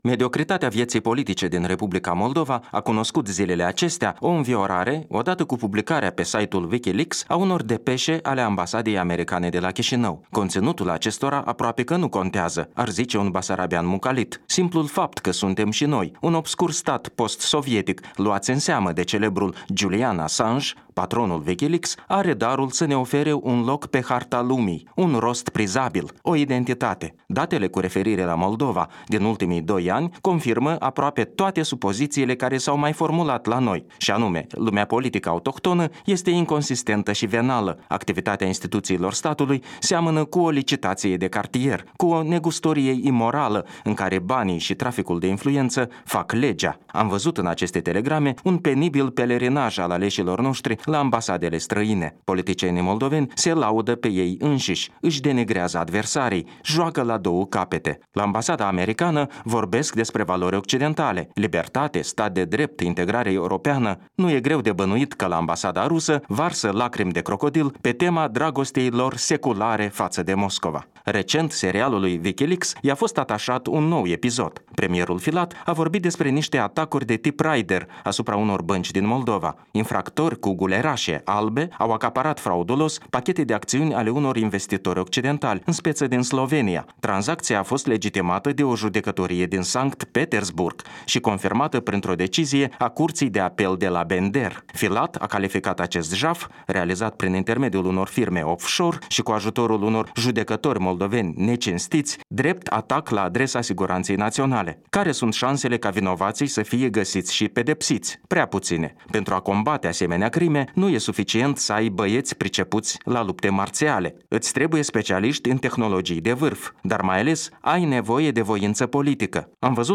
Un punct de vedere